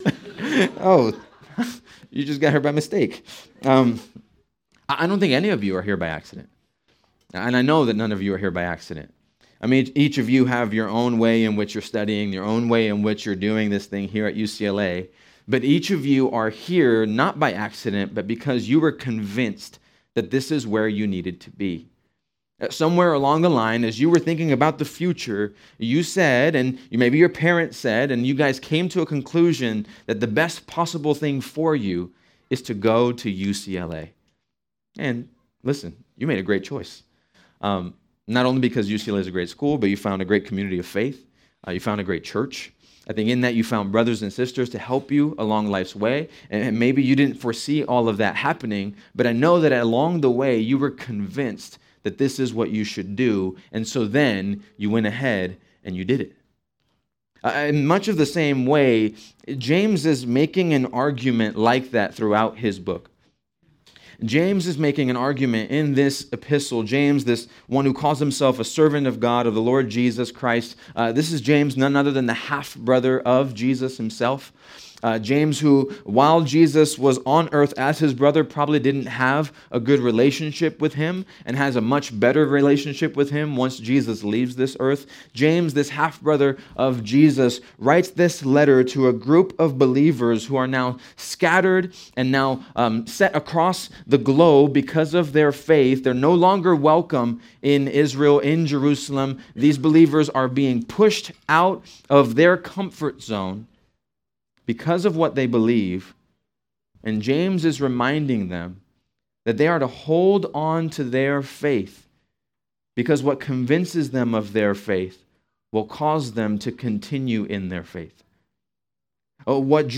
November 8, 2025 - Sermon | Grace on Campus UCLA | Grace Community Church